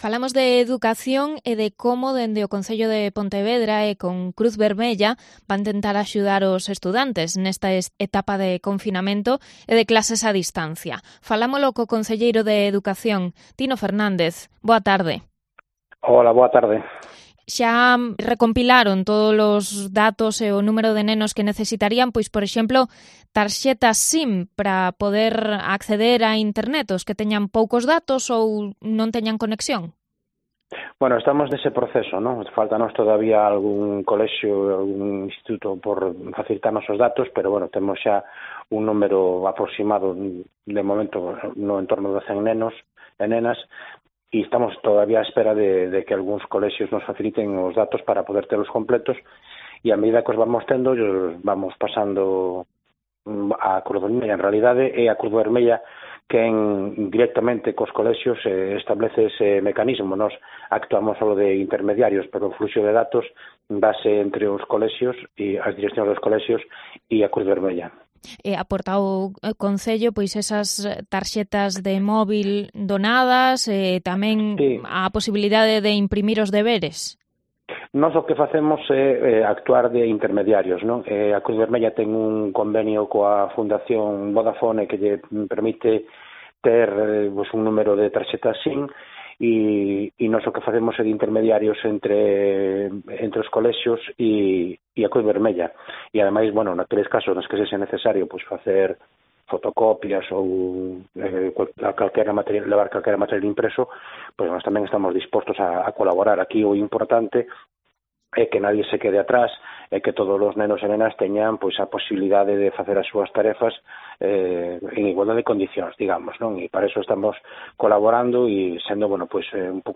Entrevista al concejal de Pontevedra Tino Fernández sobre educación